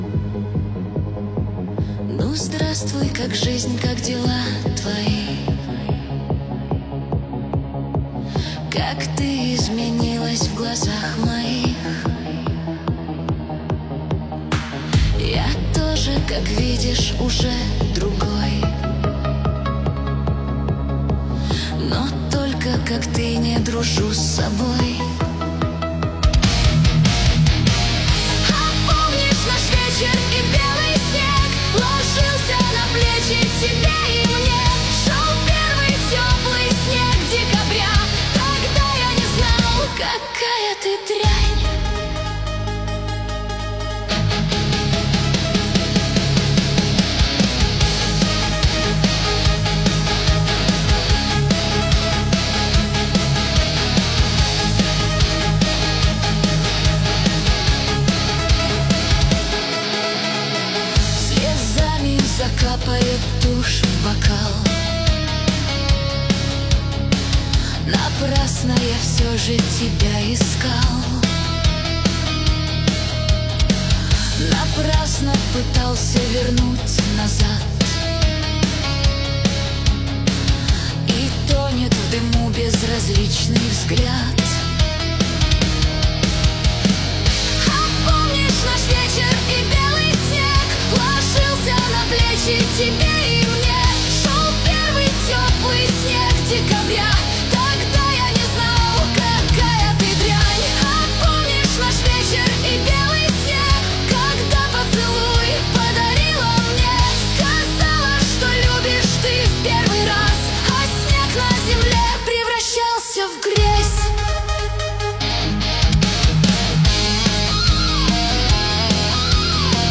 Качество: 320 kbps, stereo
Каверы 2025, Рок, Рок музыка 2025